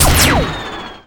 pshoot1.ogg